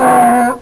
donkey.wav